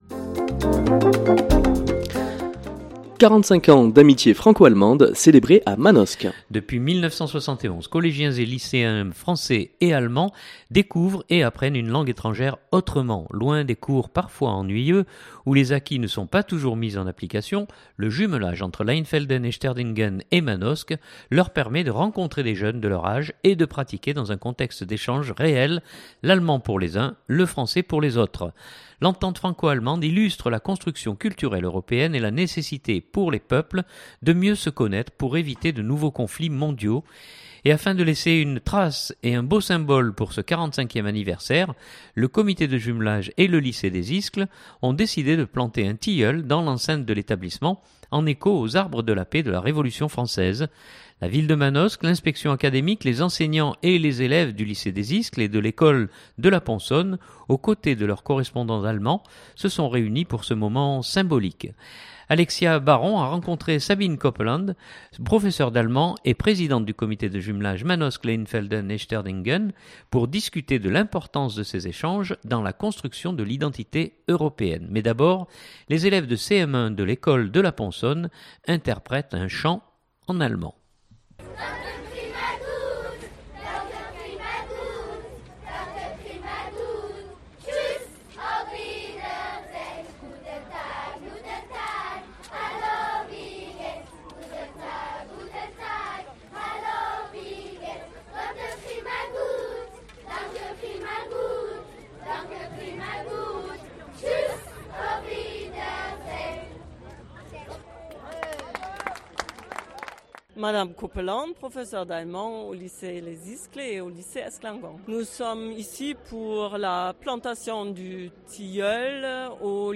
Mais d’abord, les élèves de CM1 de l’école de la Ponsonne interprétent un chant… en allemand.